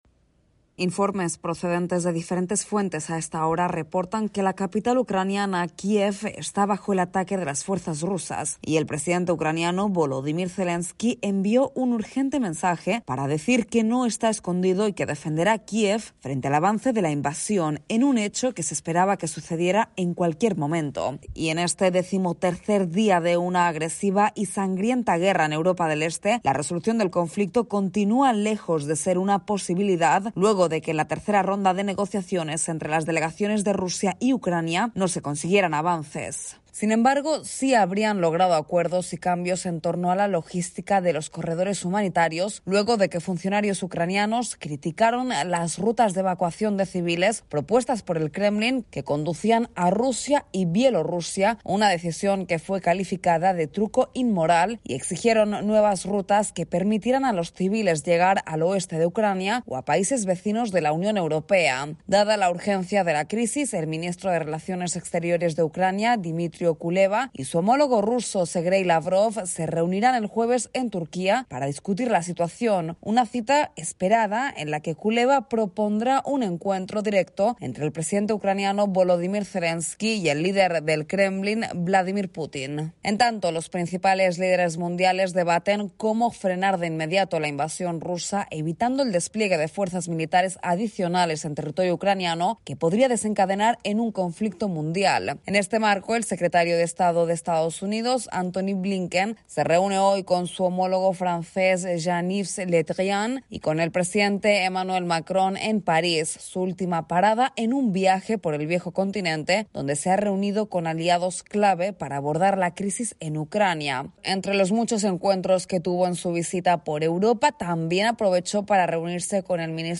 La comunidad internacional busca una solución al conflicto armado luego de la invasión rusa a Ucrania mientras ambos negocian nuevas rutas para evacuar a los civiles que huyen de la guerra. Informa